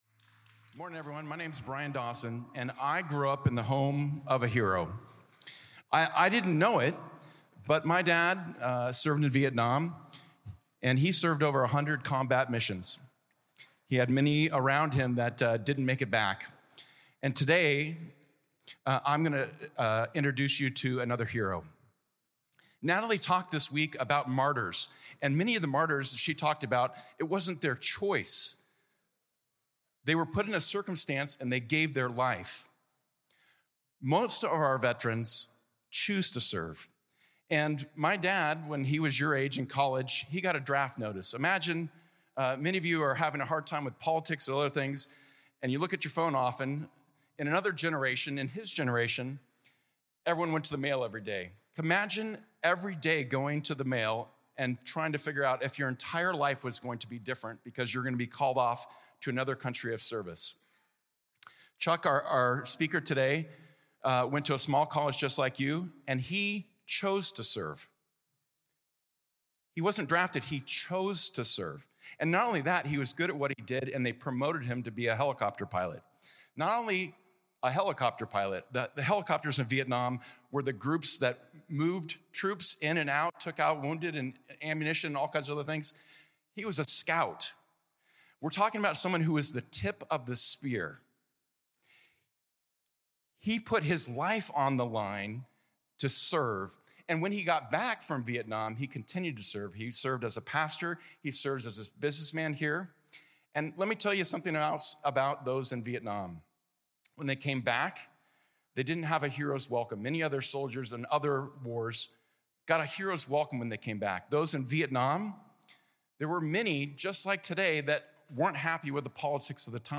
This talk was given in chapel on Friday, November 8th, and 2024 God Bless you.